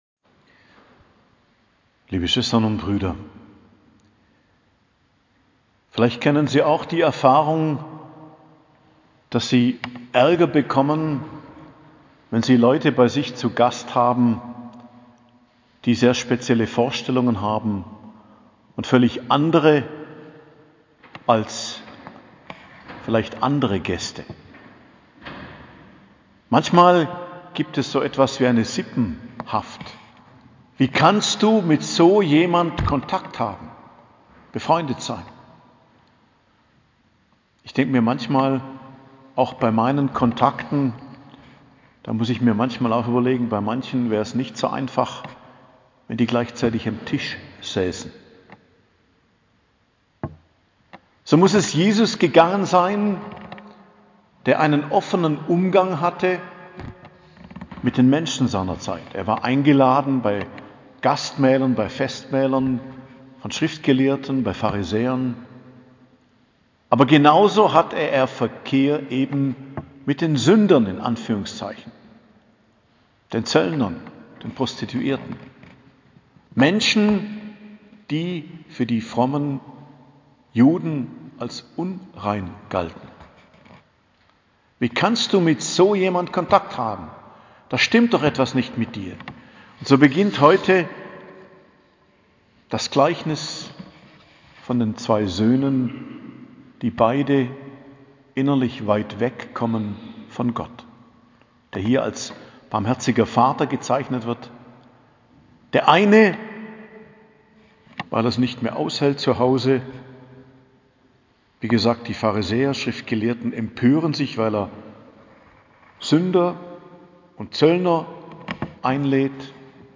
Predigt zum 4. Fastensonntag am 27.03.2022 ~ Geistliches Zentrum Kloster Heiligkreuztal Podcast